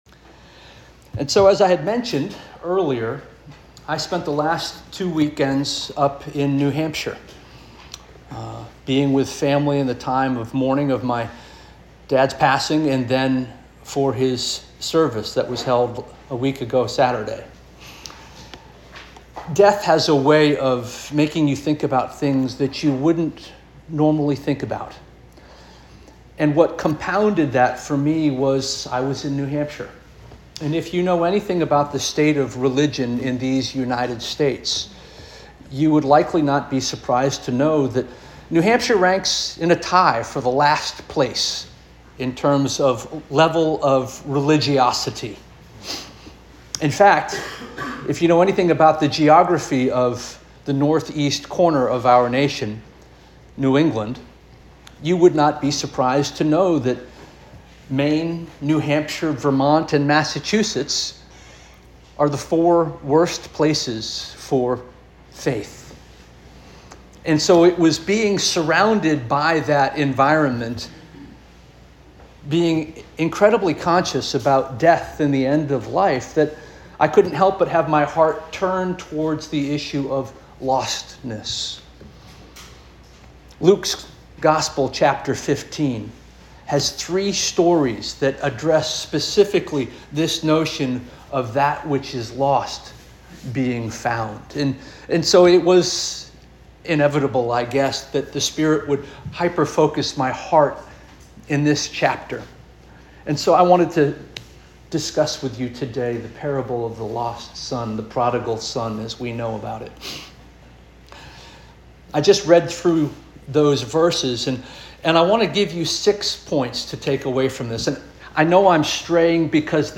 June 1 2025 Sermon - First Union African Baptist Church